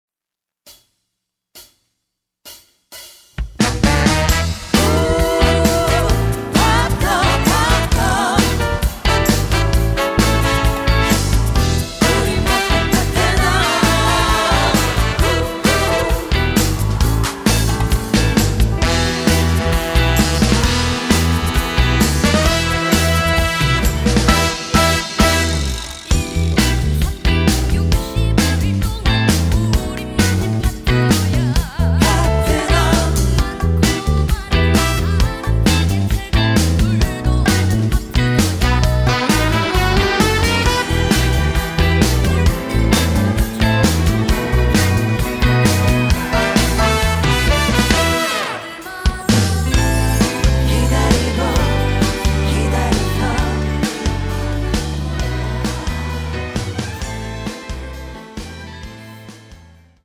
음정 원키 3:18
장르 가요 구분 Voice MR